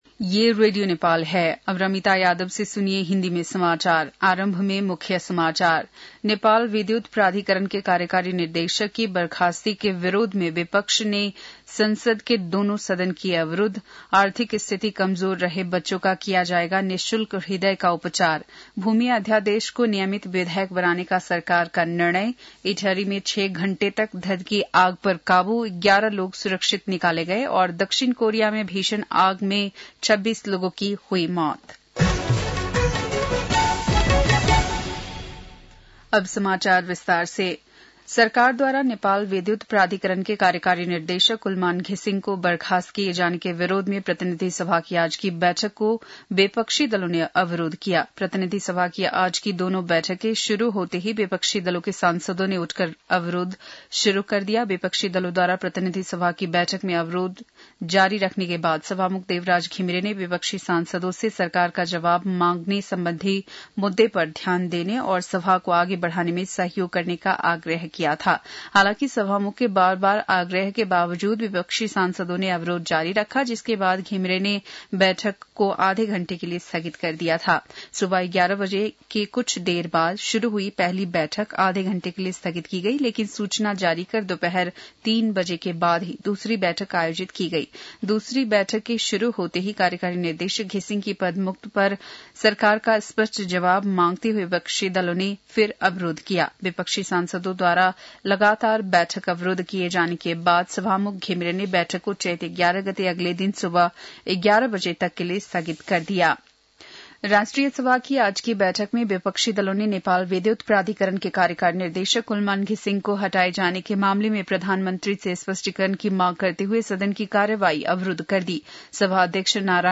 बेलुकी १० बजेको हिन्दी समाचार : १३ चैत , २०८१